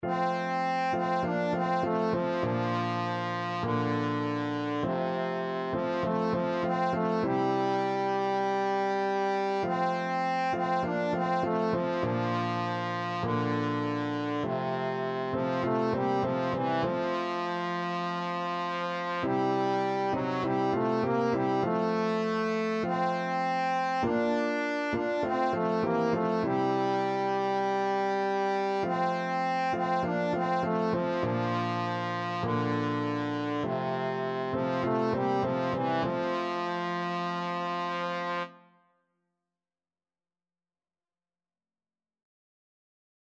4/4 (View more 4/4 Music)
Classical (View more Classical Trombone Music)